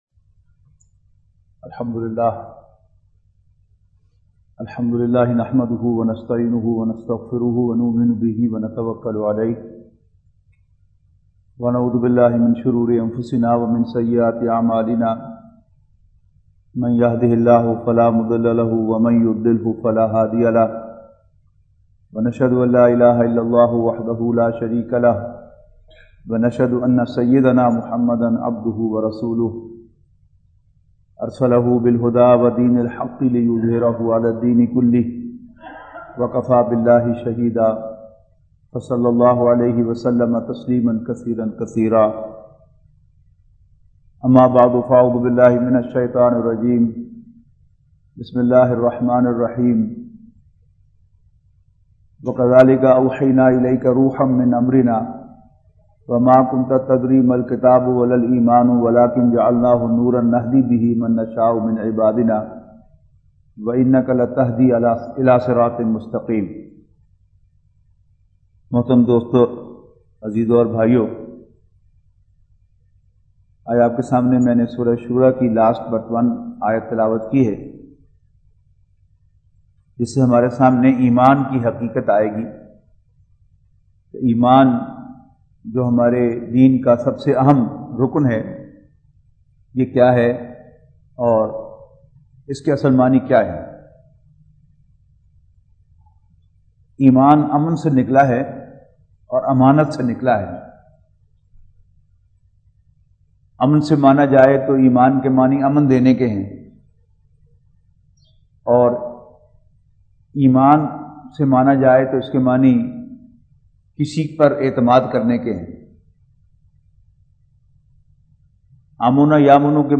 Khitab E Juma / Audio / 60 Iman Ki Haqeeqat